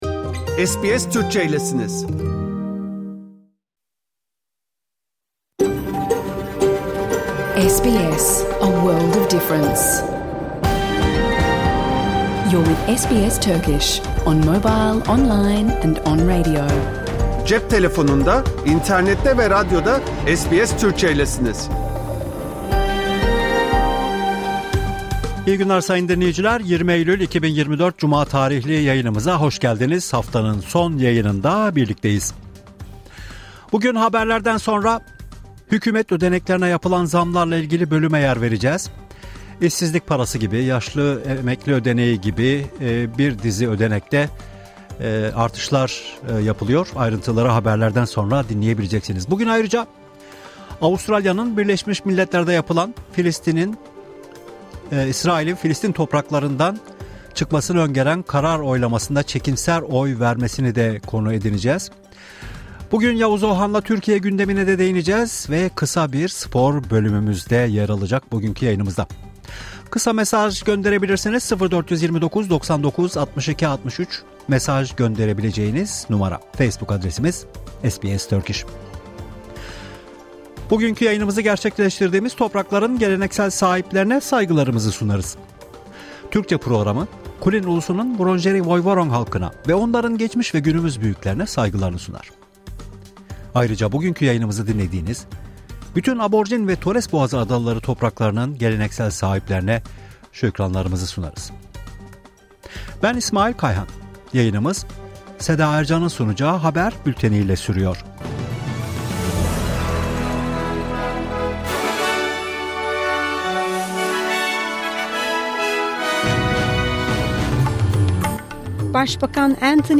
Hafta içi Salı hariç hergün her saat 14:00 ile 15:00 arasında yayınlanan SBS Türkçe radyo programını artık reklamsız, müziksiz ve kesintisiz bir şekilde dinleyebilirsiniz.